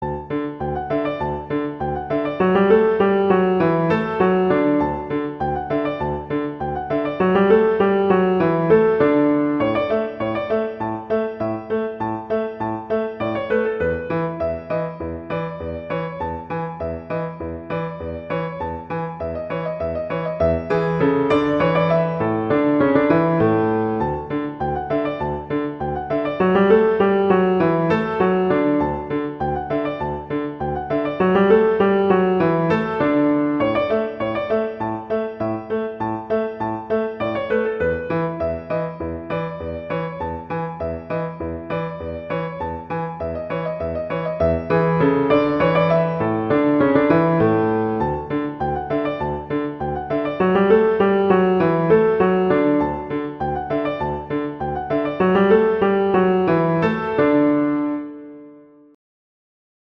classical, children
♩=100 BPM